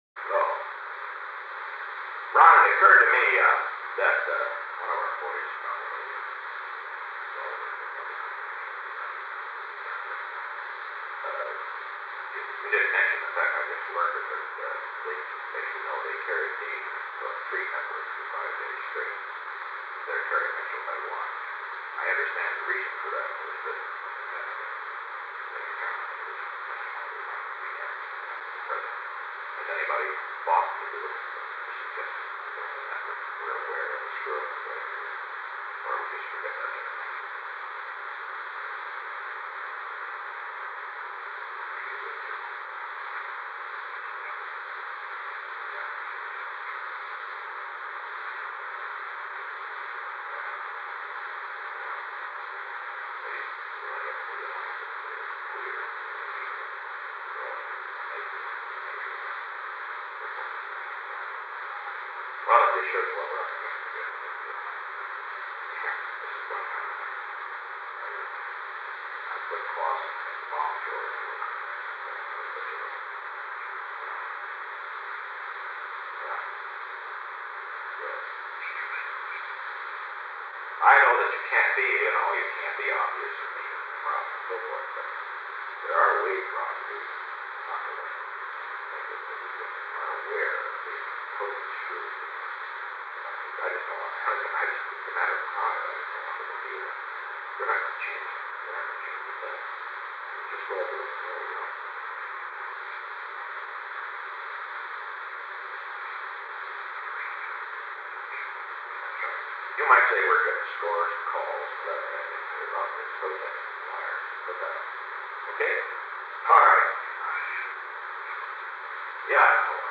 Secret White House Tapes
Conversation No. 448-17
Location: Executive Office Building
The President talked with Ronald L. Ziegler